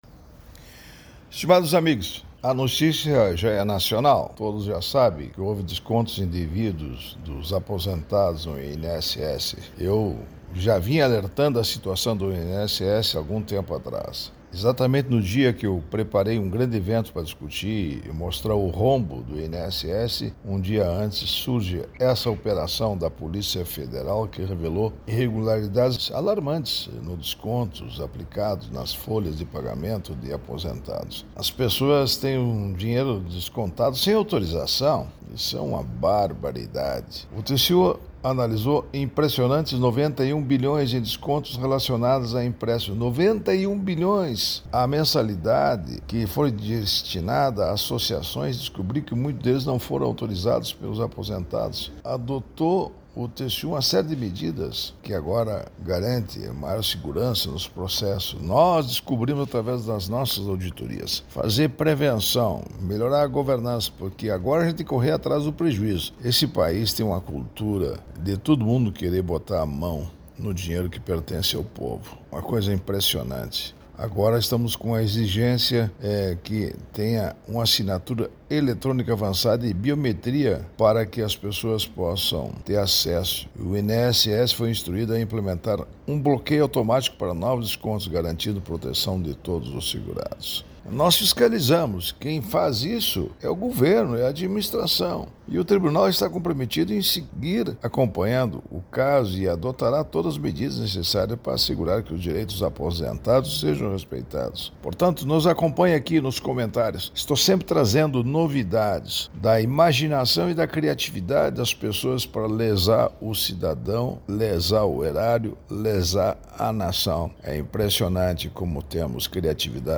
Comentário do Ministro do TCU, Augusto Nardes.